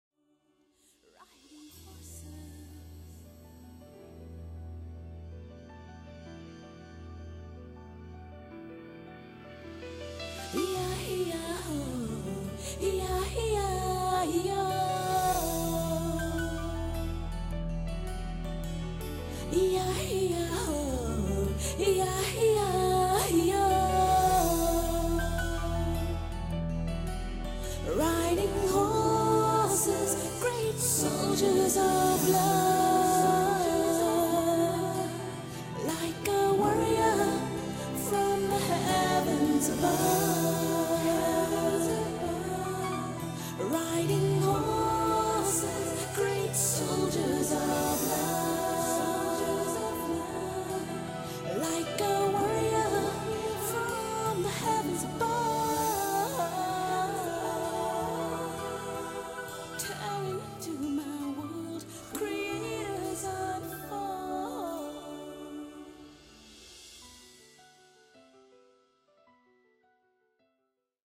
【所屬類別】 CD唱片　　爵士及藍調
優雅的現代爵士樂風，韻味與品味的完美結合...